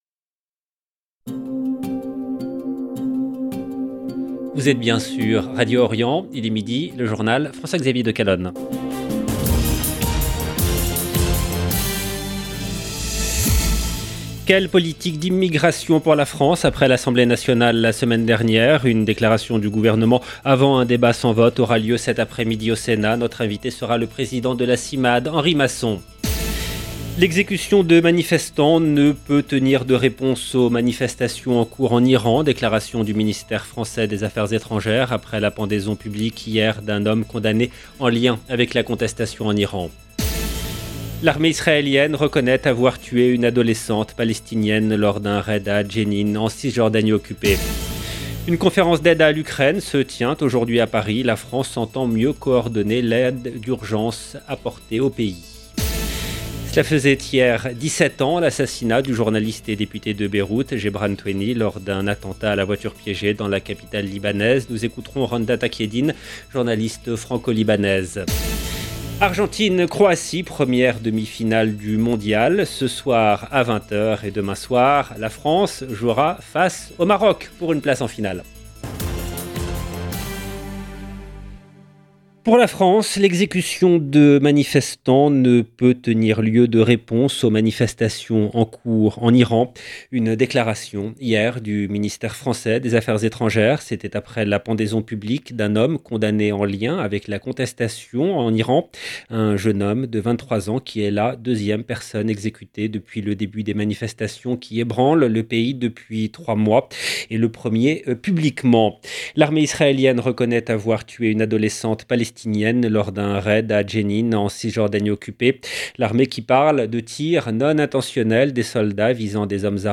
LE JOURNAL EN LANGUE FRANCAISE DE MIDI DU 13/12/22